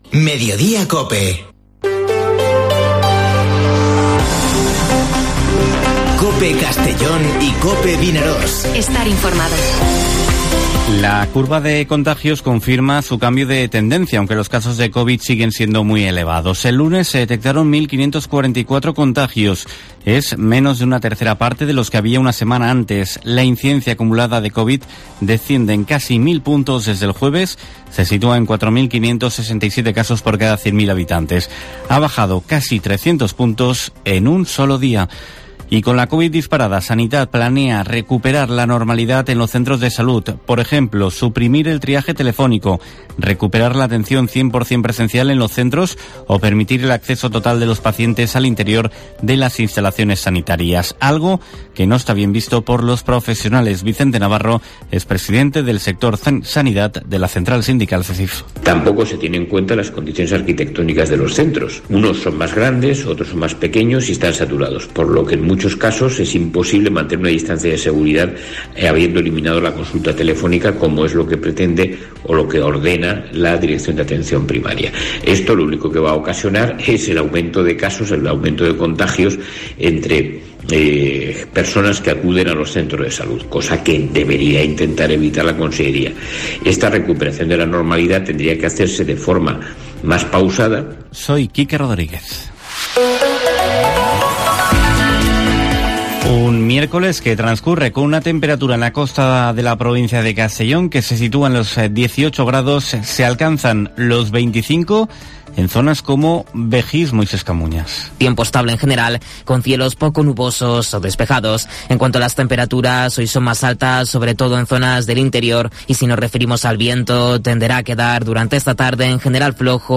Informativo Mediodía COPE en la provincia de Castellón (02/02/2022)